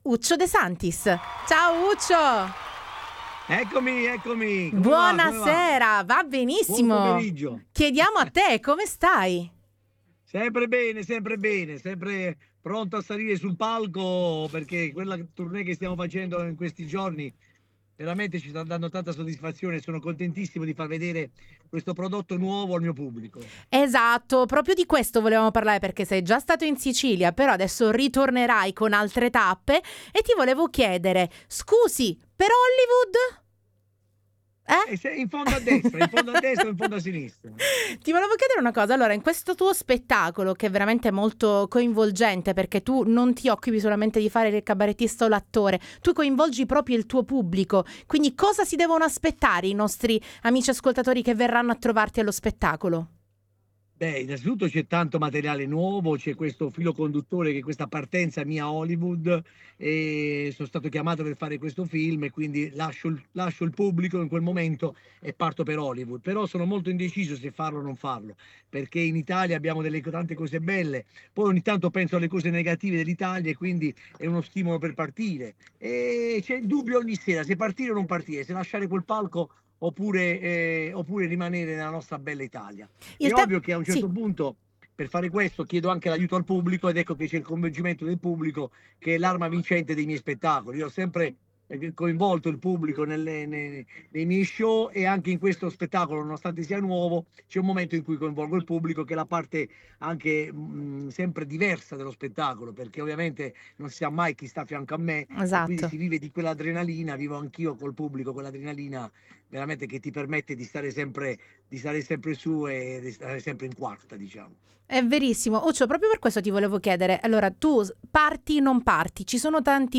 Interviste Tandem 16/04/2026 12:00:00 AM / TANDEM Condividi: Parliamo con Uccio De Santis presenta il suo nuovo spettacolo: “Scusi, per Hollywood?” in tour in tutta Italia !!